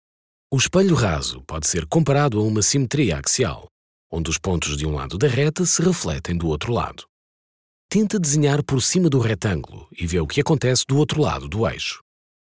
Male
Approachable, Authoritative, Confident, Conversational, Corporate, Deep, Engaging, Friendly, Smooth, Versatile, Warm
Portuguese (European)
SHOWREEL MP3.mp3
Microphone: Rode NT1-A